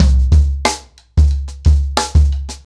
Expositioning-90BPM.13.wav